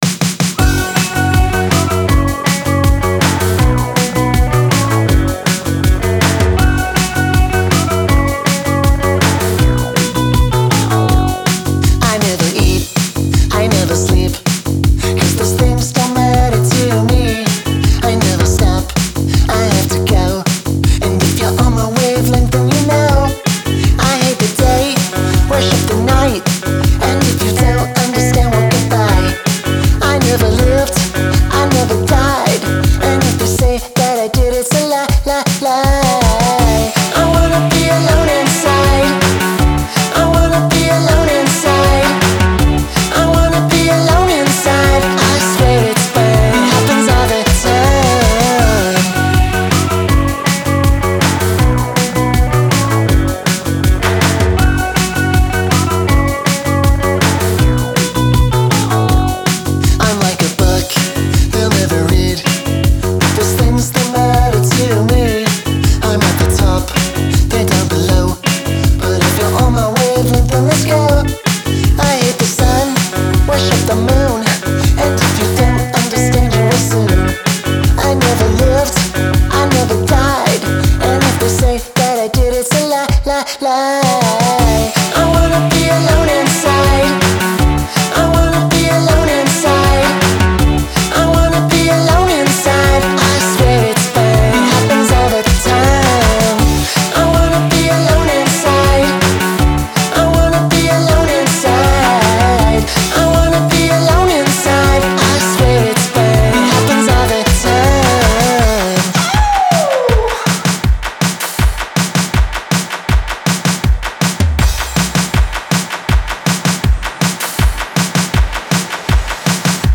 Genre: Indie, Dance Punk